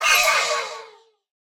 Minecraft Version Minecraft Version snapshot Latest Release | Latest Snapshot snapshot / assets / minecraft / sounds / mob / allay / item_taken1.ogg Compare With Compare With Latest Release | Latest Snapshot
item_taken1.ogg